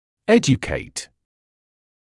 [‘eʤukeɪt][‘эджукэйт]обучать, давать знания, просвещать